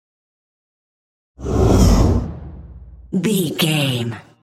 Whoosh fast trailer
Sound Effects
Fast
futuristic
tension
whoosh